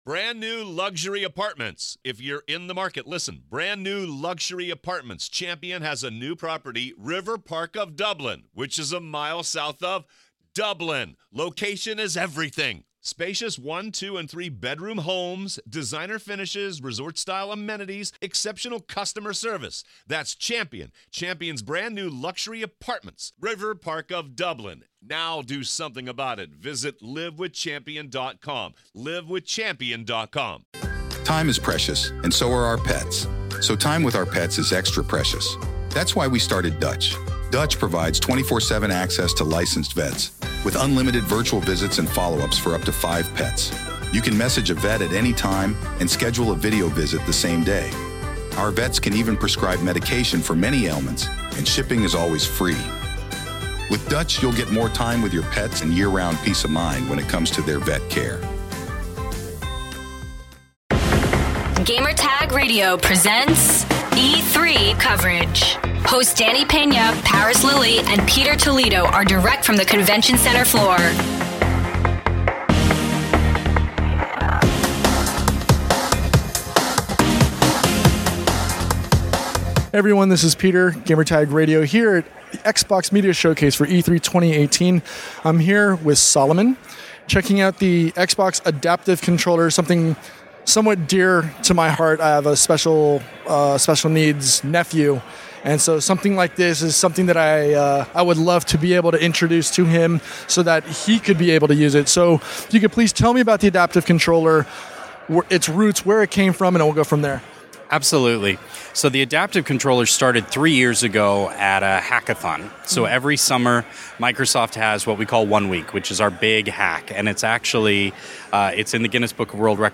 Xbox Adaptive controller Interview.